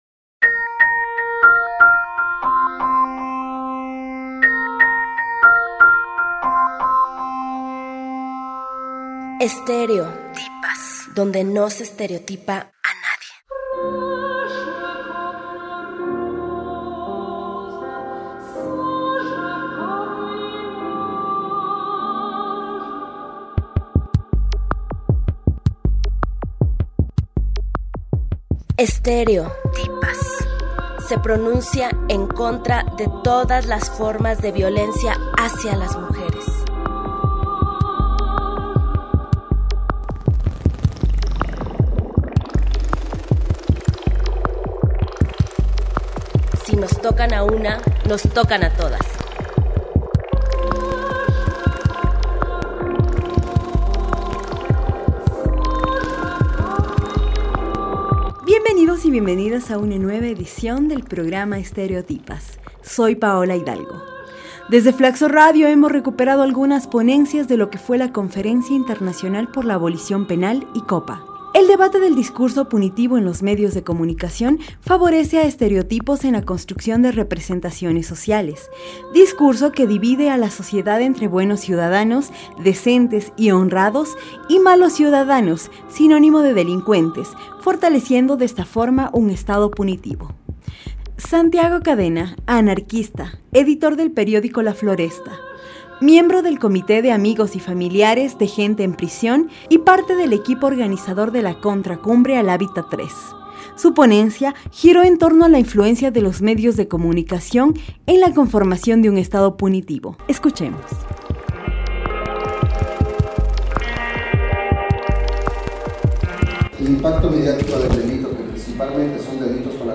Hemos recuperado algunas ponencias de lo que fue la ICOPA, Conferencia Internacional por la Abolición Penal, realizada en el mes de junio en Quito.